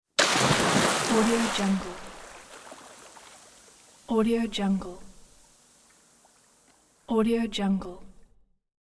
دانلود افکت صوتی شیرجه رفتن در استخر
Hard Dive and Splash Into Swimming Pool royalty free audio track is a great option for any project that requires urban sounds and other aspects such as a water, splash and swimming.
Sample rate 16-Bit Stereo, 44.1 kHz
Looped No